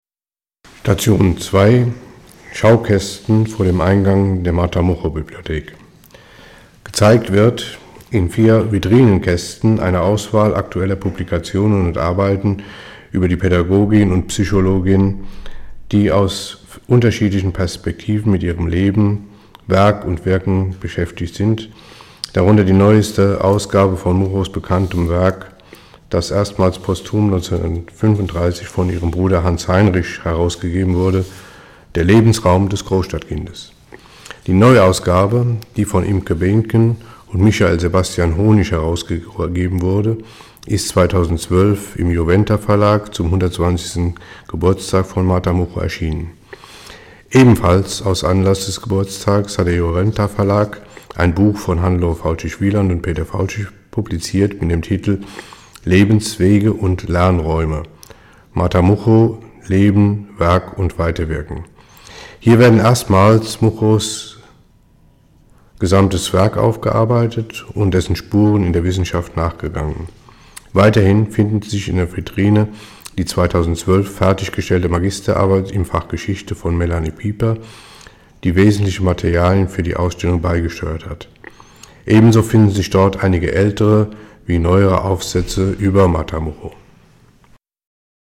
Audioguide: Station 2)